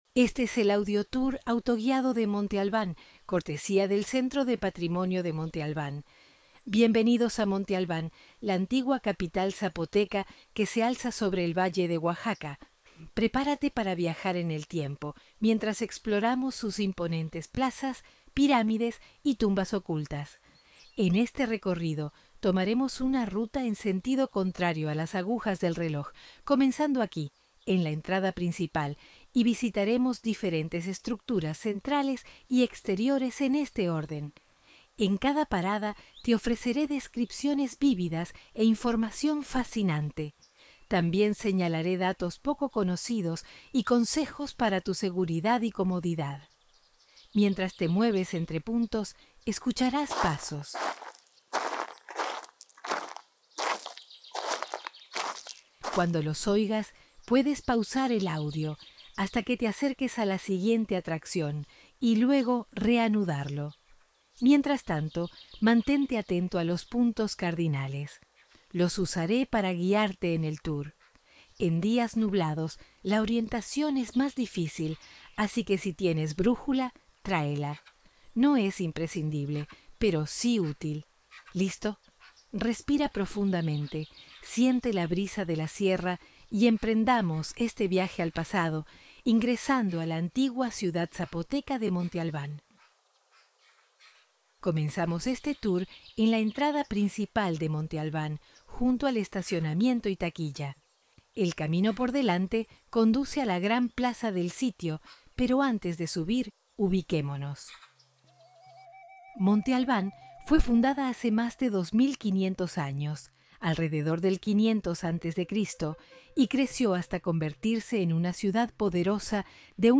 Audioguía Premium — 16 Paradas